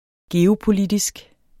Udtale [ geoˈ- ]